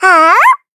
Taily-Vox_Attack3_kr_a.wav